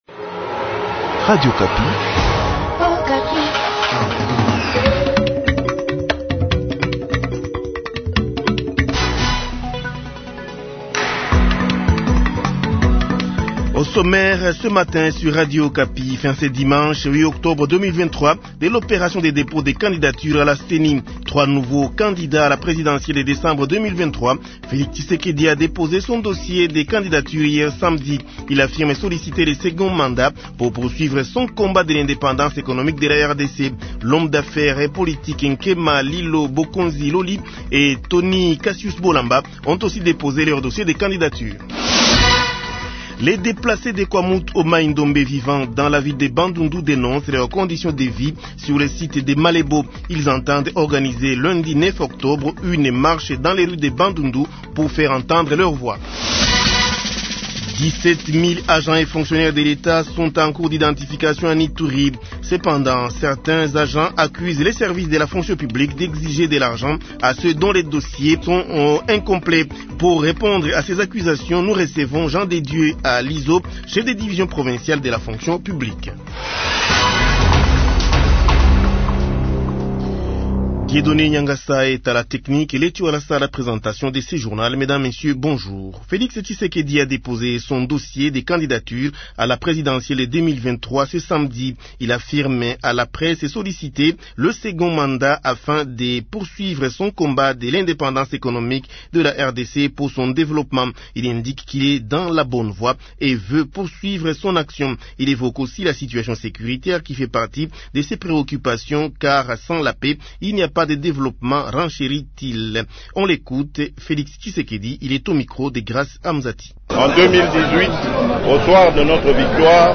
Journal Matin 7heures Français